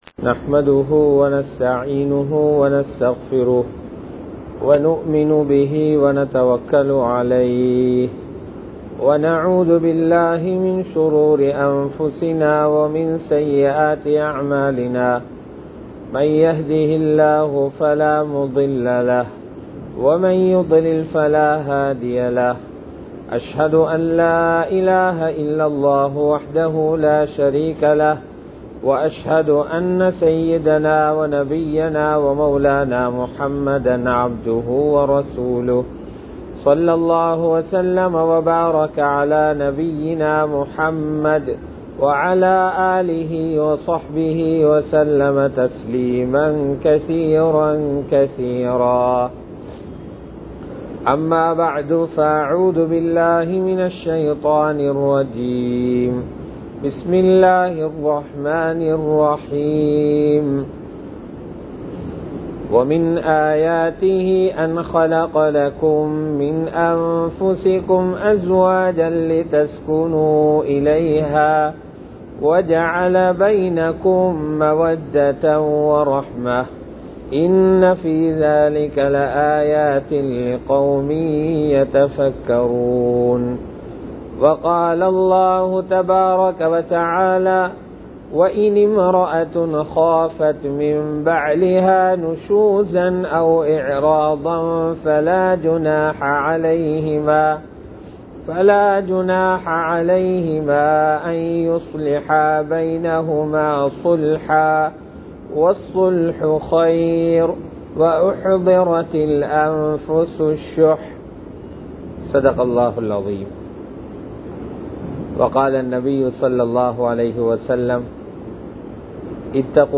வெற்றிகரமான குடும்ப வாழ்க்கை | Audio Bayans | All Ceylon Muslim Youth Community | Addalaichenai
Kollupitty Jumua Masjith